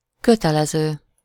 Ääntäminen
Synonyymit requis contraignant obligé Ääntäminen France: IPA: /ɔ.bli.ɡa.twaʁ/ Haettu sana löytyi näillä lähdekielillä: ranska Käännös Ääninäyte 1. kötelező Suku: f .